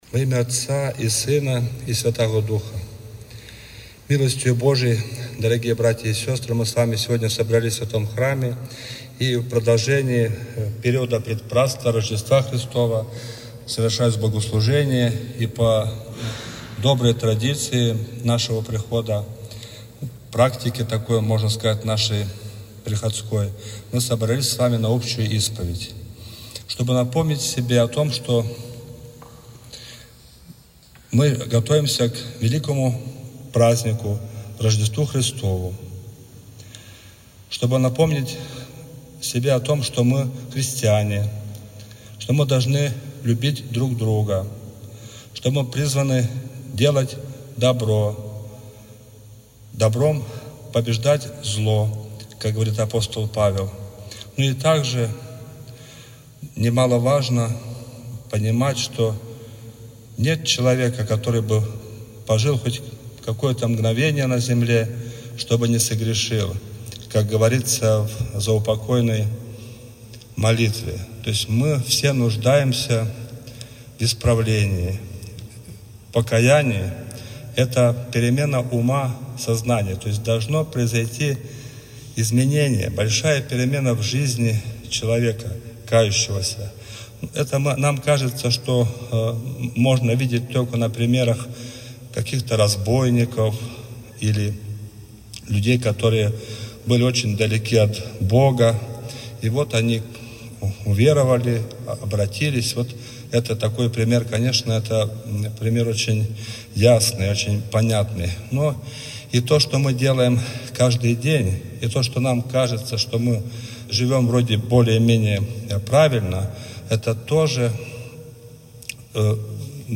Проповедь-перед-общей-исповедью.mp3